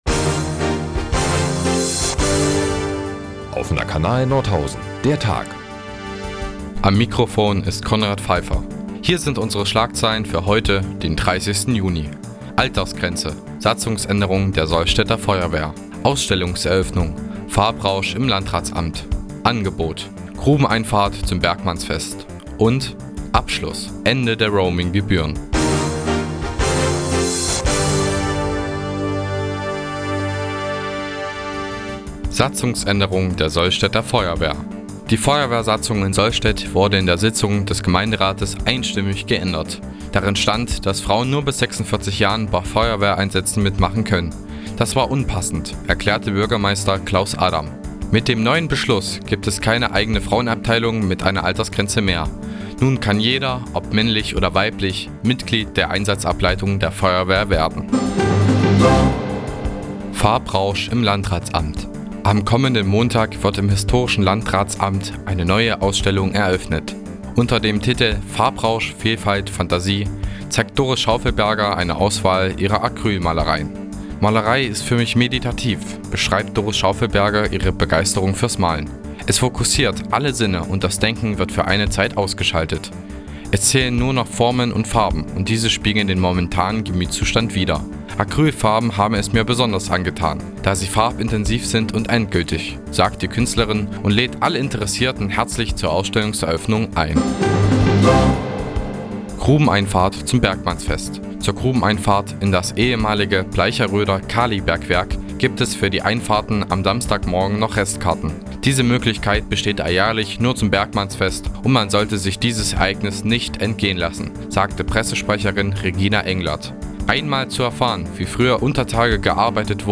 Die tägliche Nachrichtensendung des OKN ist jetzt hier zu hören.